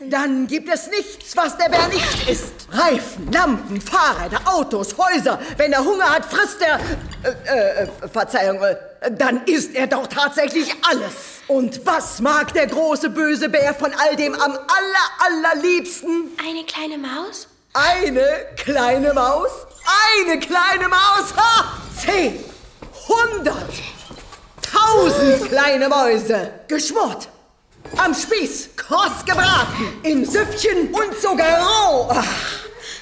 Synchronstudio : Taurus Film, München
Aufseherin des Waisenhauses